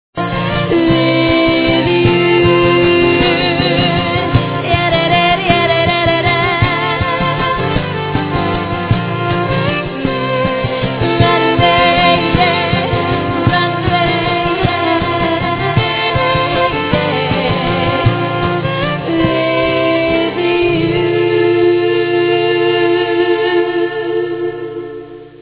(Acoustic version)